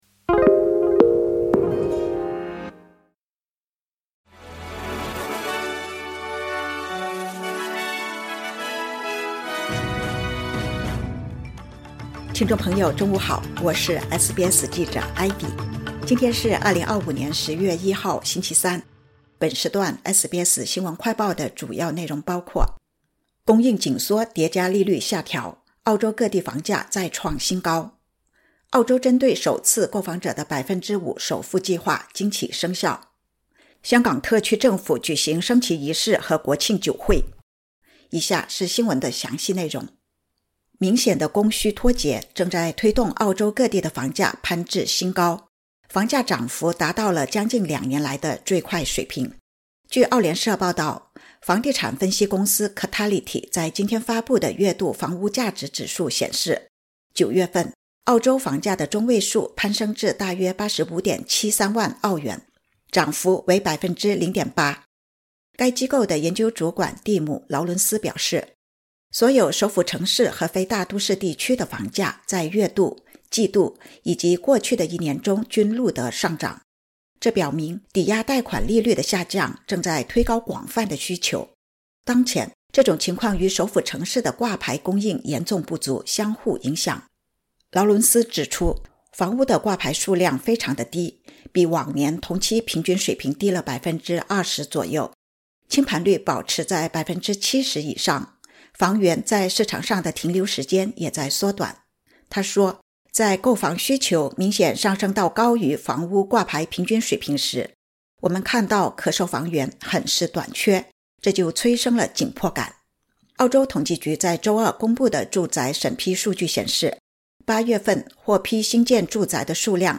【SBS新闻快报】供应紧缩和利率下调叠加 澳洲各地房价攀至新高